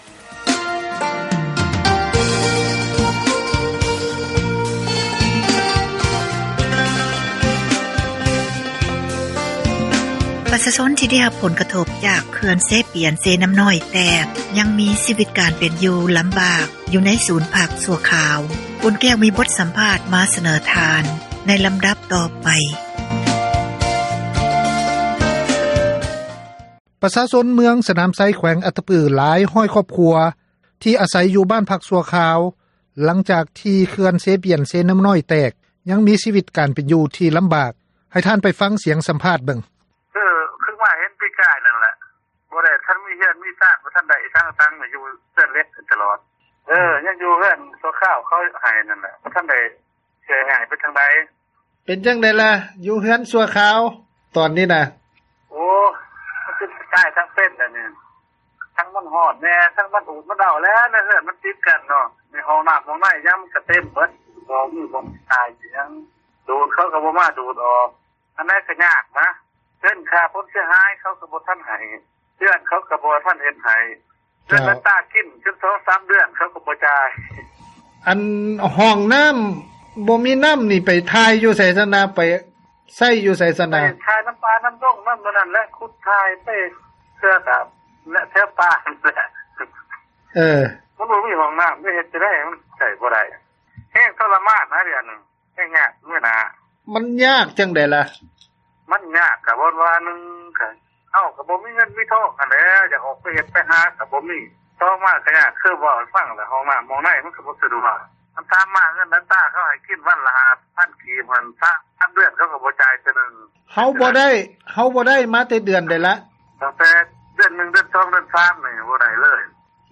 ມີບົດສັມພາດ ມາສເນີທ່ານ ໃນລໍາດັບ ຕໍ່ໄປ…